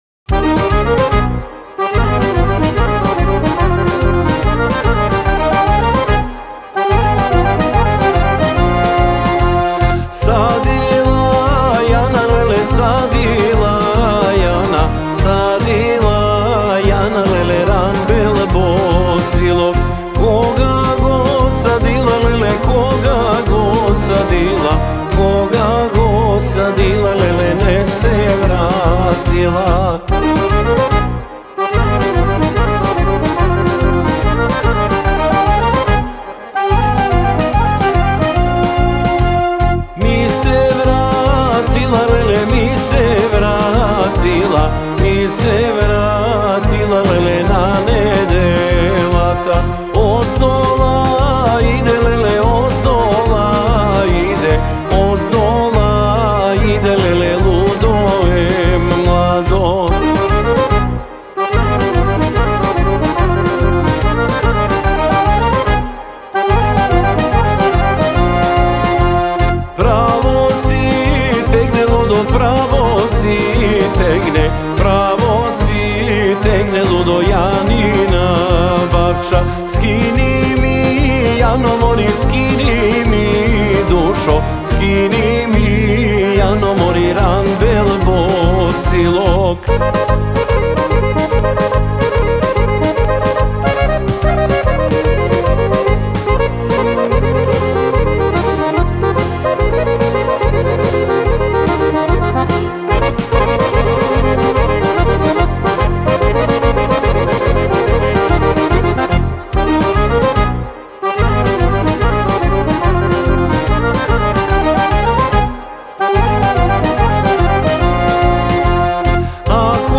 Композицијата „Садила Јана“ е народна македонска песна и дел од музичкиот албум „Носталгија“.